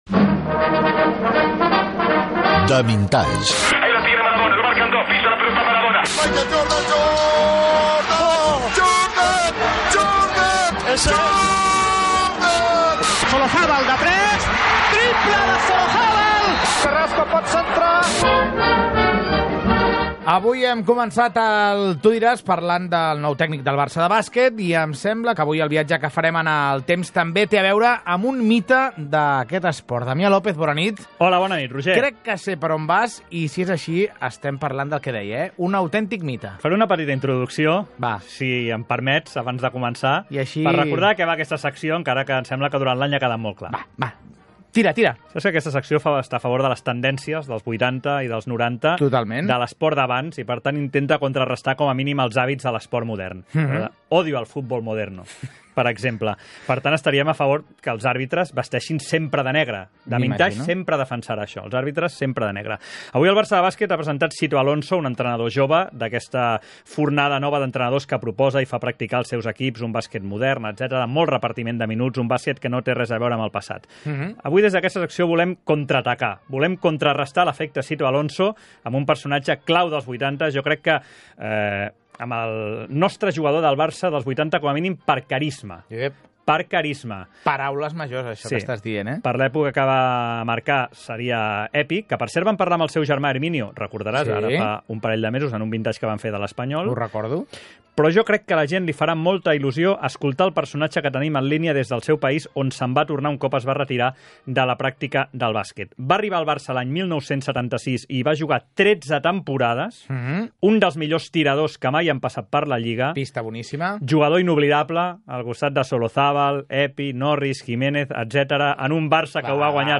En conversación telefónica con la República Dominicana, Chicho Sibilio recordó su paso por el Palau Blaugrana entre los años 1976 y 1989.